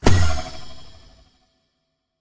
Minecraft Version Minecraft Version 25w18a Latest Release | Latest Snapshot 25w18a / assets / minecraft / sounds / mob / illusion_illager / mirror_move1.ogg Compare With Compare With Latest Release | Latest Snapshot